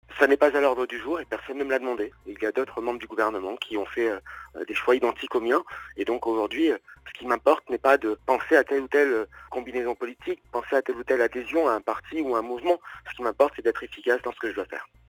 Voici quelques extraits de cet entretien téléphonique, réalisé en direct.